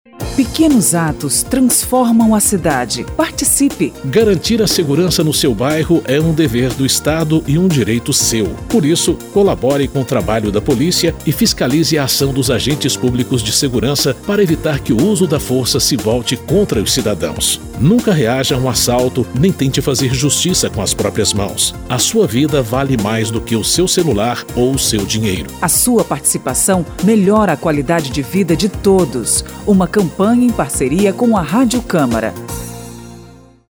São 7 spots de 30 segundos sobre saúde, transporte, educação e segurança, destacando o papel de cada um – prefeito, vereadores e cidadãos – na melhoria da vida de todos.
spot-pequenos-atos-6.mp3